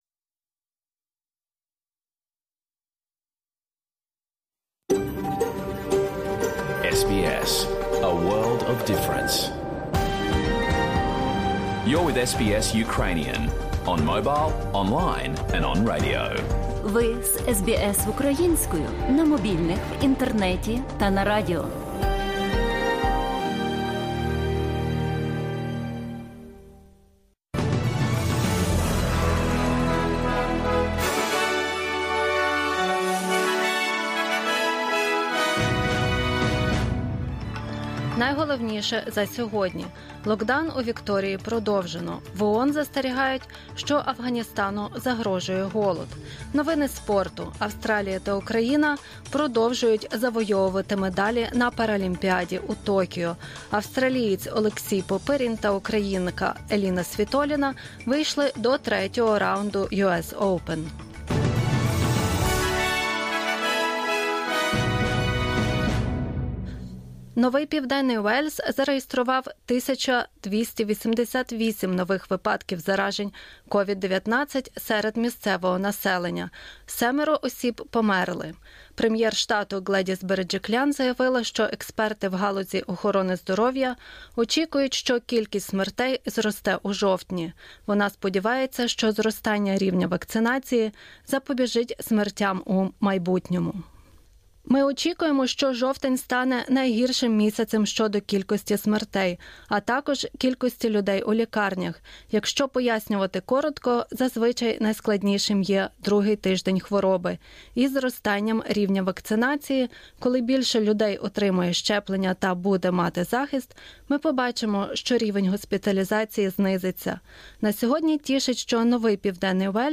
SBS новини українською - 02 вересня 2021